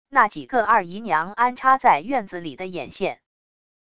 那几个二姨娘安插在院子里的眼线wav音频生成系统WAV Audio Player